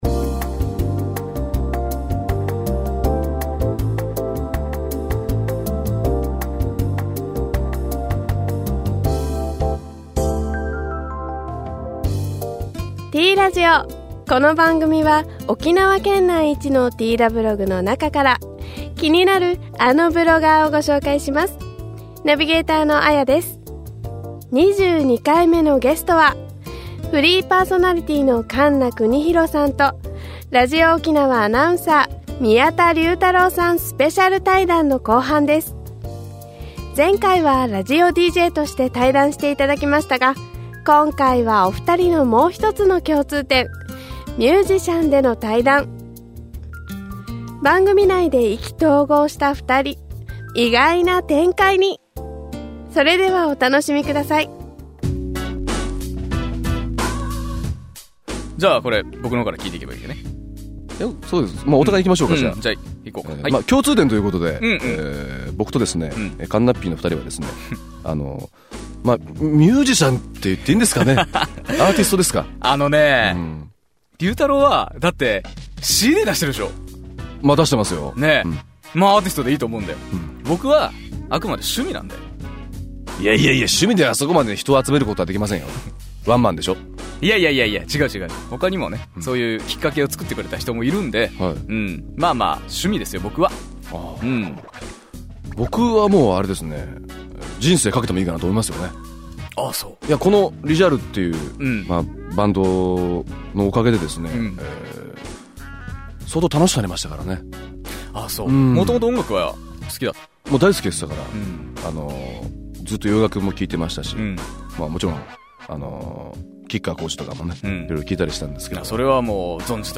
前回はラジオDJとして、対談して頂きましたが 今回はお二人のもう一つの共通点、「ミュージシャン」での 対談。